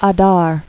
[A·dar]